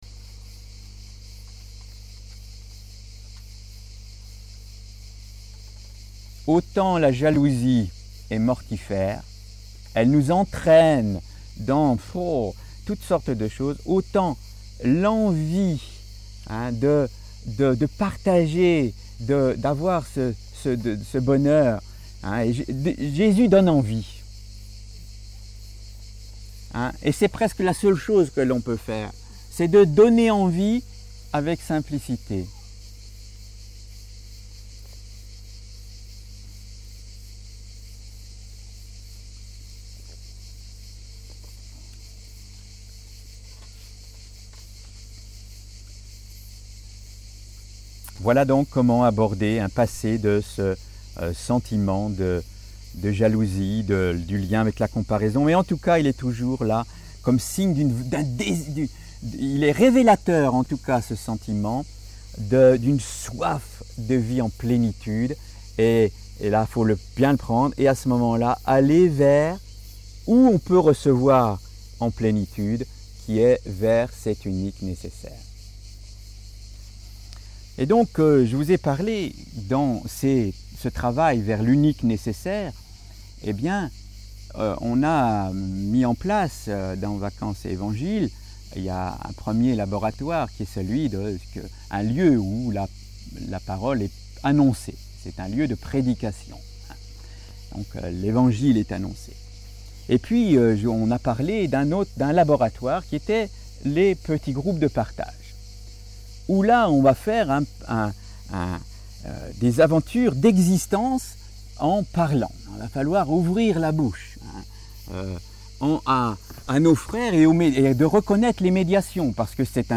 Conférences de VetE 2006 - 7VetE - Réjouissez-vous (...)